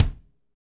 Index of /90_sSampleCDs/Northstar - Drumscapes Roland/DRM_Funk/KIK_Funk Kicks x
KIK FNK K0AL.wav